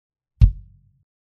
If you want a more dead sounding kick drum with less boom then you might want to smack that hole right in the middle of the kick.